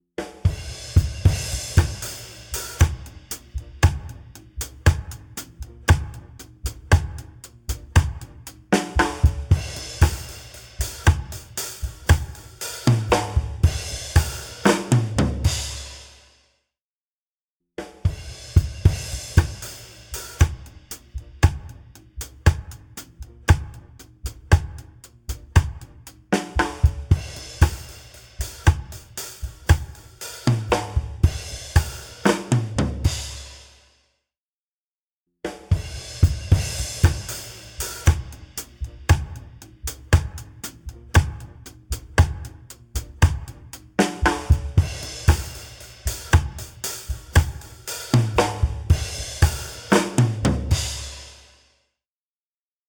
EChannel | Drums | Preset: Peak Distortion
EChannel-Drum-Peak-Distortion.mp3